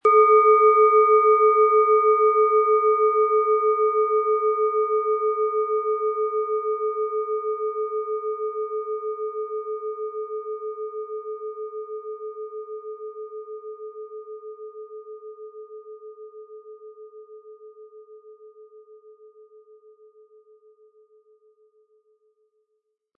Tibetische Kopf- und Schulter-Klangschale, Ø 11,7 cm, 180-260 Gramm, mit Klöppel
Sie möchten den schönen Klang dieser Schale hören? Spielen Sie bitte den Originalklang im Sound-Player - Jetzt reinhören ab.
Im Preis enthalten ist ein passender Klöppel, der die Töne der Schale schön zum Schwingen bringt.